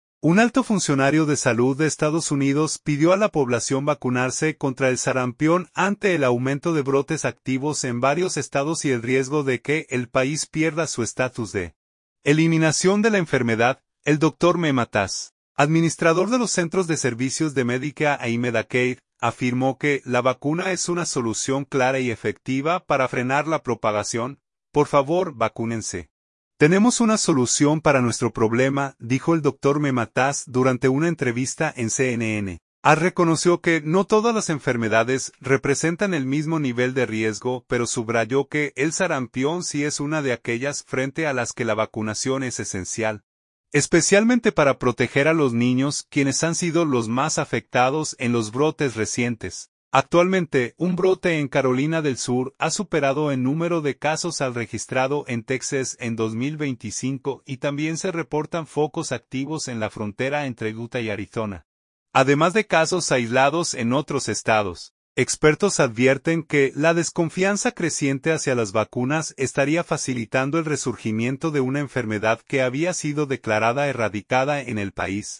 Durante una entrevista en CNN, Oz reconoció que no todas las enfermedades representan el mismo nivel de riesgo, pero subrayó que el sarampión sí es una de aquellas frente a las que la vacunación es esencial, especialmente para proteger a los niños, quienes han sido los más afectados en los brotes recientes.